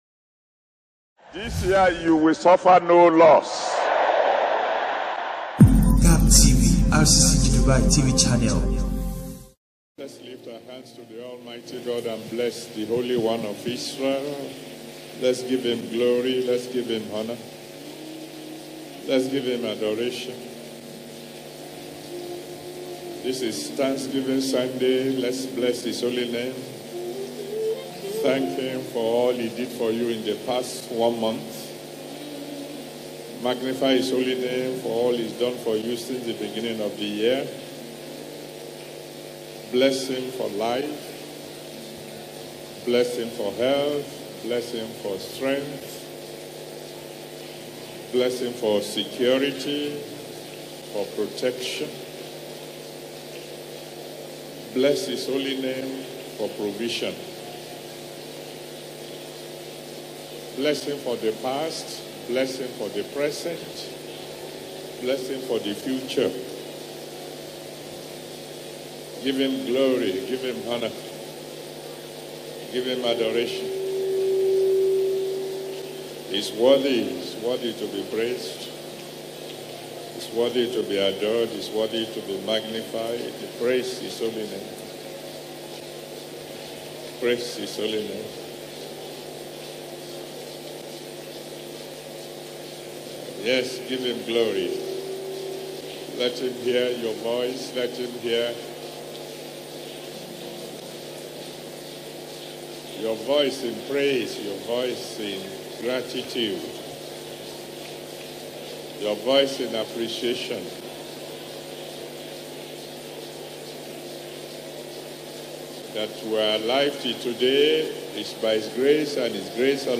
Pastor_E_A_Adeboye_Sermon_NO_MORE_WAITING.mp3